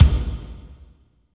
35.07 KICK.wav